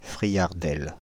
Friardel (French pronunciation: [fʁijaʁdɛl]
Fr-Friardel.ogg.mp3